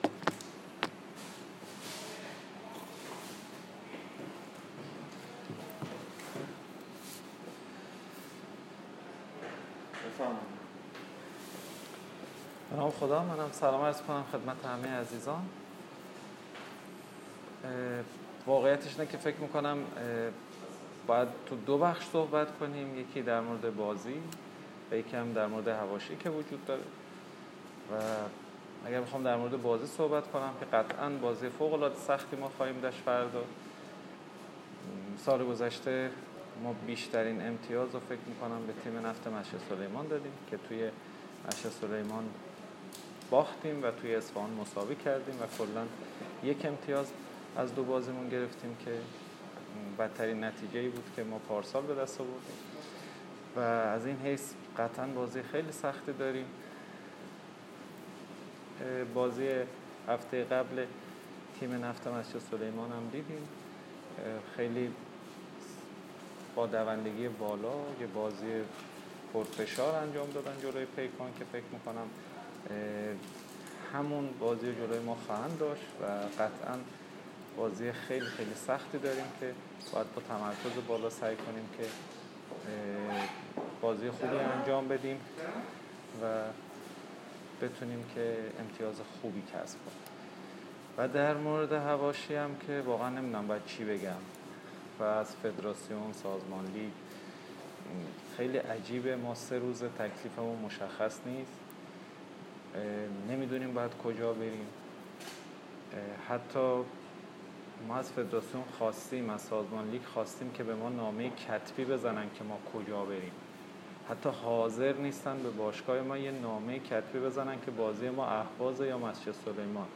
کنفرانس مطبوعاتی سرمربی تیم سپاهان و مربی تیم نفت مسجد سلیمان پیش از دیدار دو تیم در چارچوب هفته دوم رقابت‌های لیگ برتر برگزار شد.
کنفرانس خبری